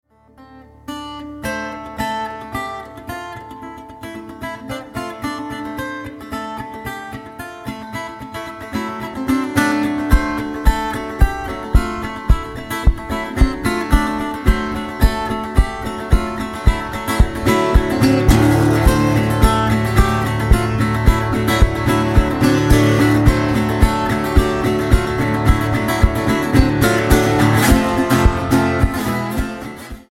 Alternative,Blues,Folk